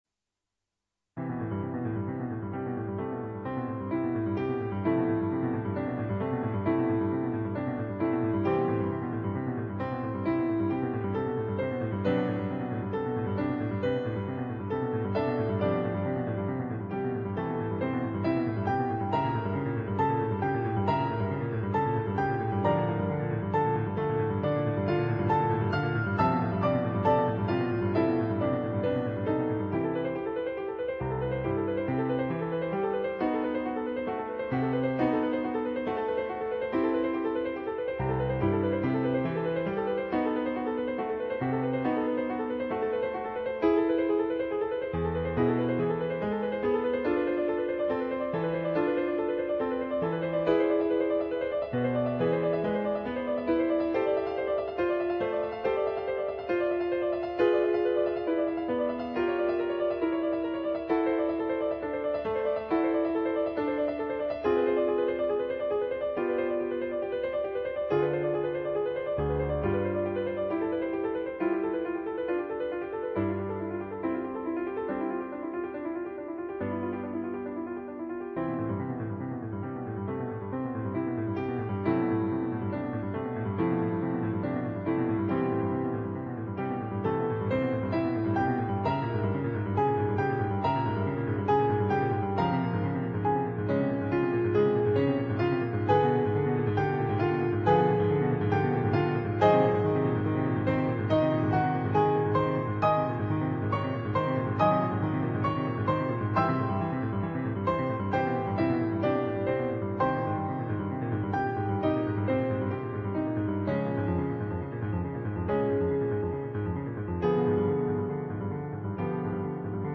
14. in C Major (2'18")
on Yamaha digital pianos.